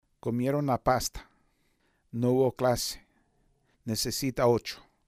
El ensordecimiento vocálico.